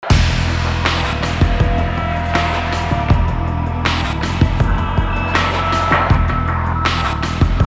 Нужен такой бас.